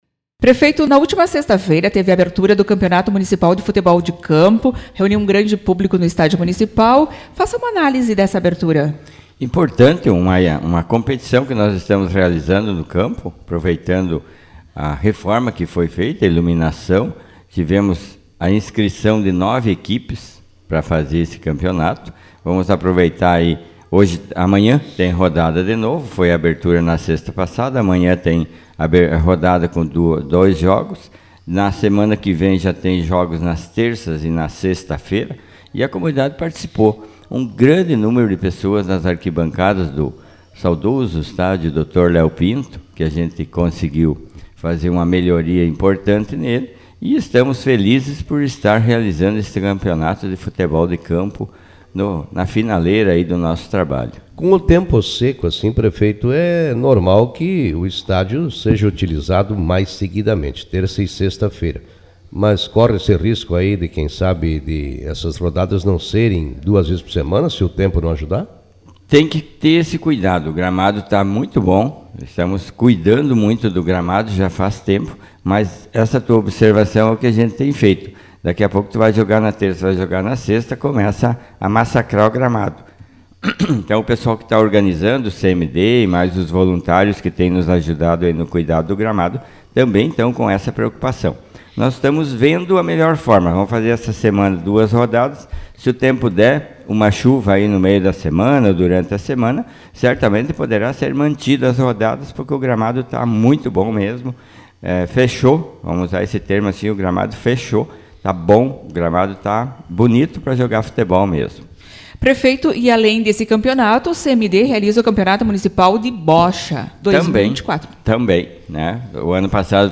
Prefeito de Iraí destaca atividades do Executivo em entrevista ao programa Café com Notícias